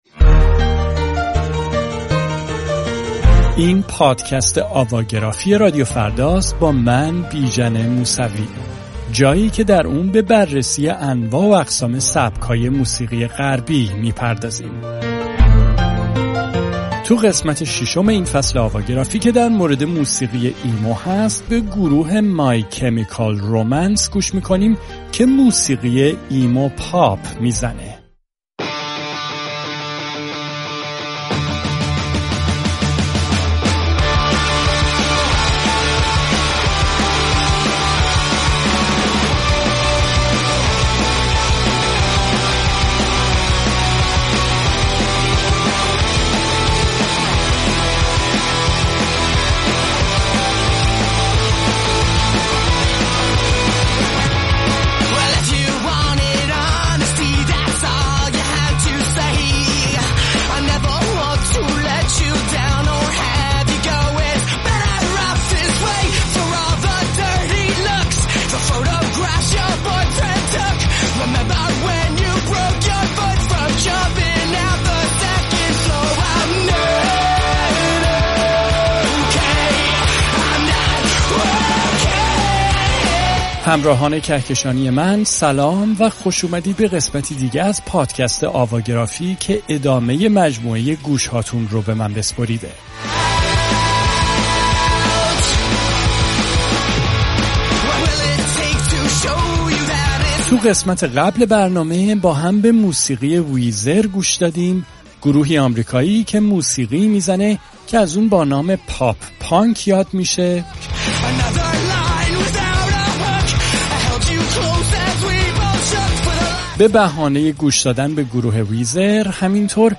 موسیقی «ایمو-پاپ»